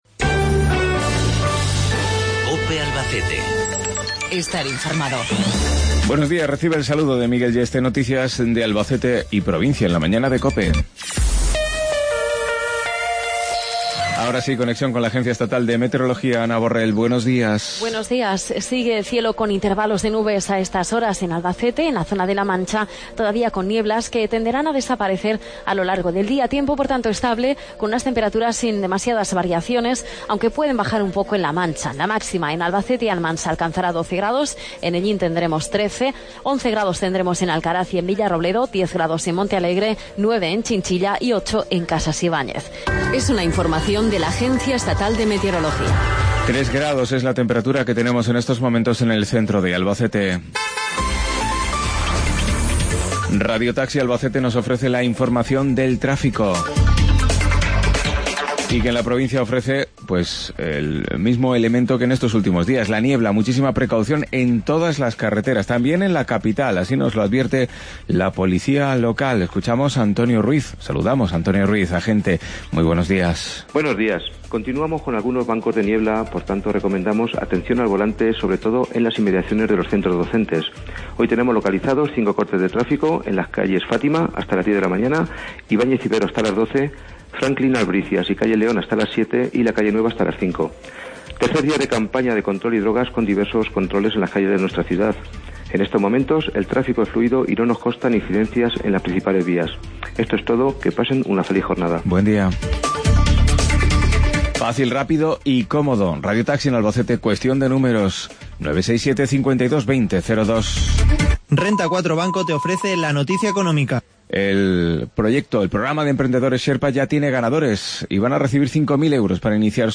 161214 Informativo local 0823